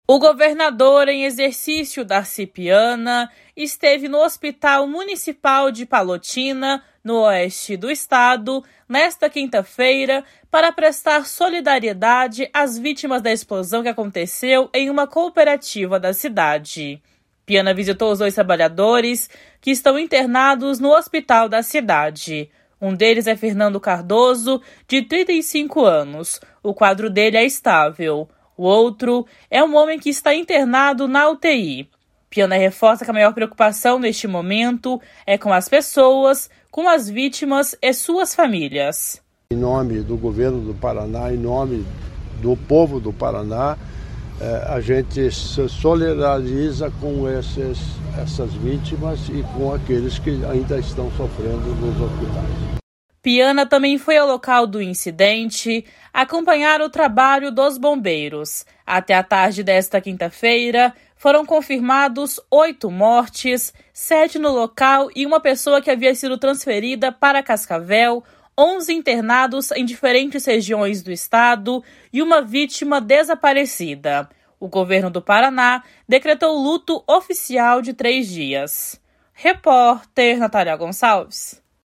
Piana reforça que a maior preocupação neste momento é com as pessoas, com as vítimas e suas famílias. // SONORA DARCI PIANA //